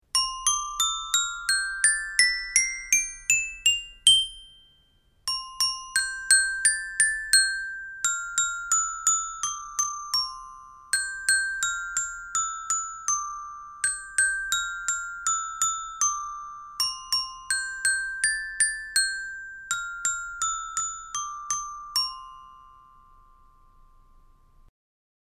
Xylófono con libro de canciones
• Material: madera, metal